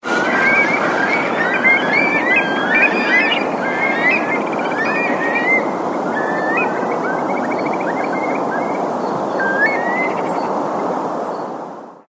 Curlews by the Orwell, Ipswich
Curlews on the banks of the Orwell. I love the sound of curlews, was surprised to find them this close to the town with that lovely bubbling sound. The bridge carries trucks from Felixstowe so there's a bit of background noise.